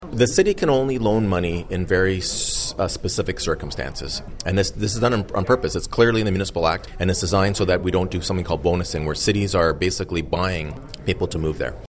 Mayor Panciuk spoke to Quinte News following the meeting.